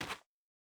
Bare Step Gravel Hard D.wav